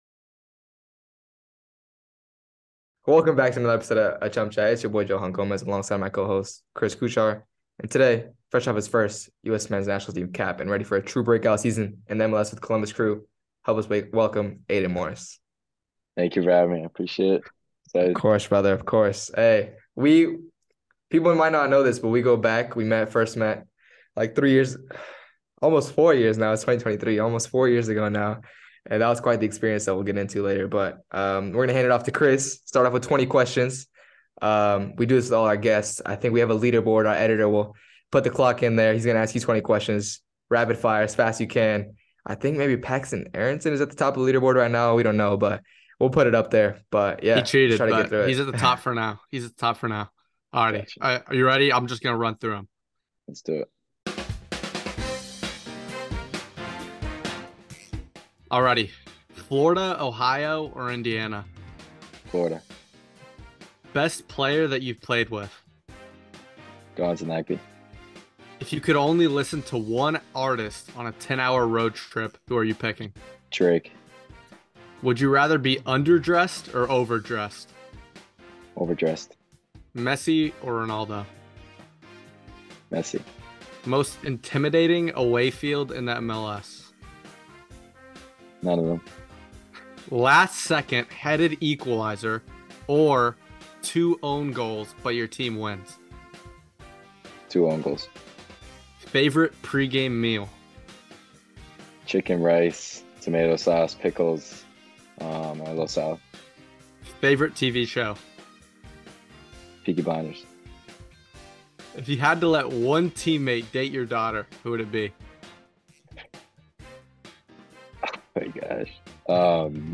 Two pro soccer players and an Average Joe try to define success through the words of some of the most successful people in sports, so that you can go and define your own!